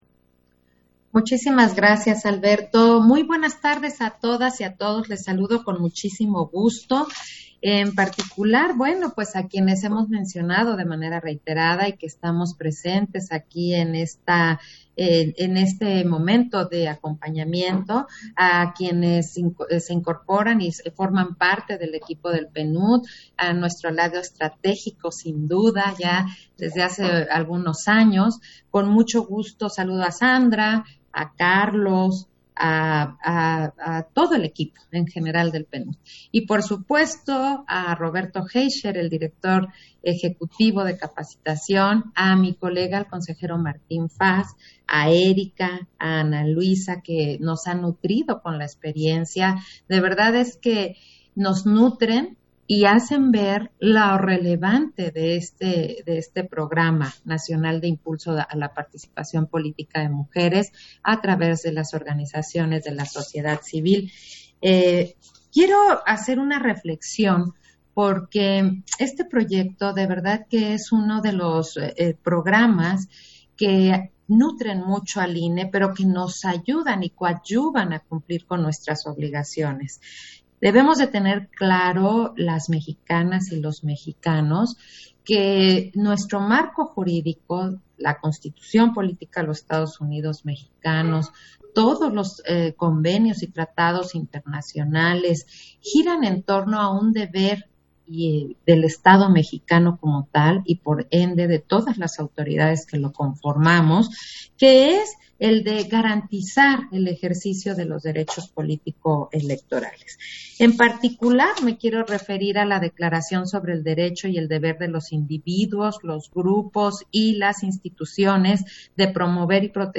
040522_AUDIO_INTERVENCIÓN-CONSEJERA-ZAVALA-CONVOCATORIA-DEL-PROGRAMA-NACIONAL-DE-IMPULSO-A-LA-PARTICIPACIÓN-POLÍTICA-DE-MUJERES - Central Electoral